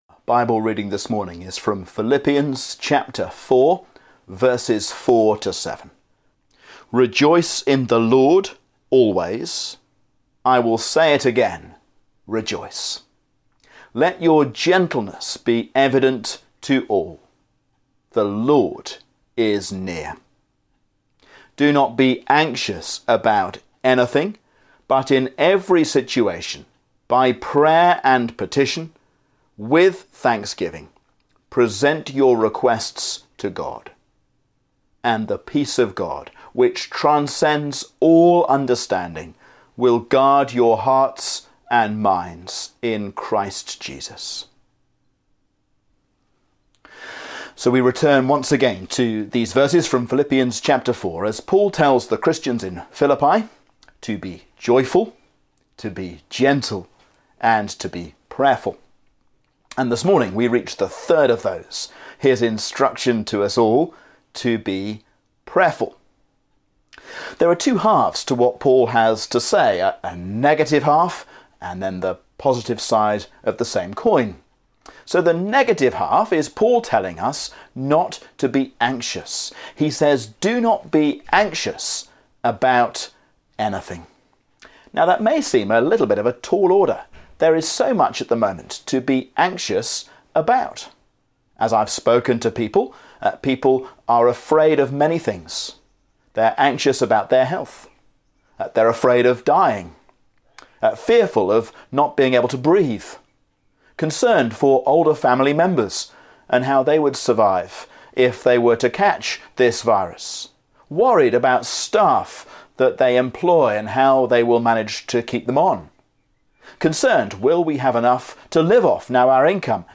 A sermon on Philippians 4:4-7